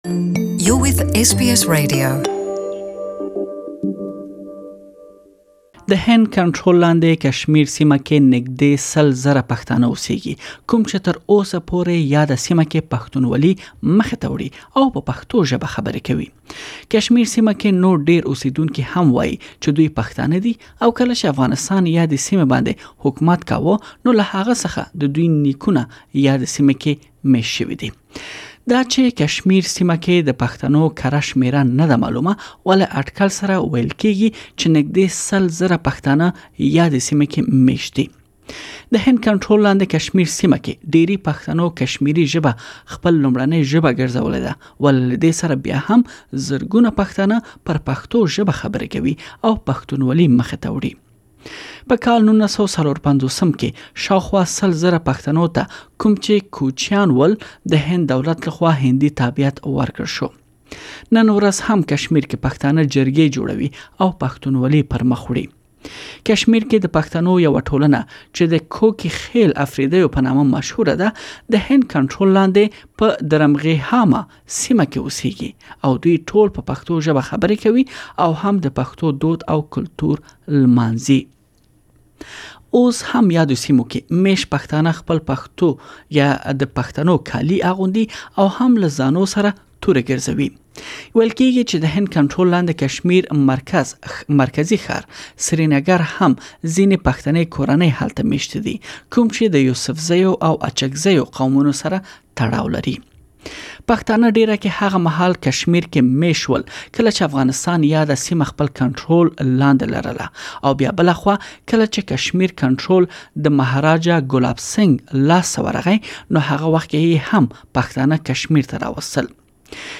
تاسو ته مو دا ټول معلومات په راډيويي رپوټ کې ځای کړي چې دلته يې اوريدلی شئ.